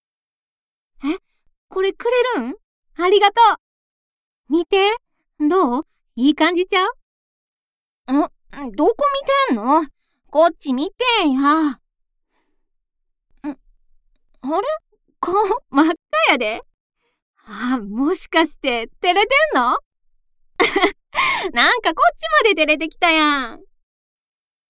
ボイスサンプル
フリートーク